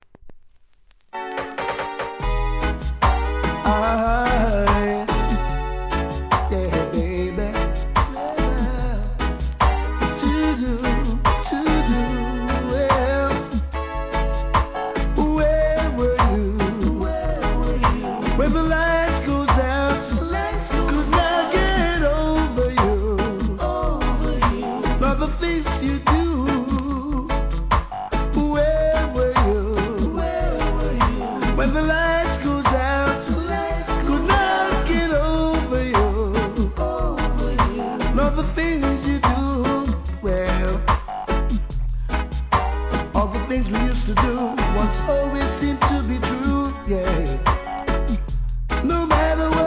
REGGAE
1998年、心地よいミディアムRHYTHMに流石のヴォーカル!!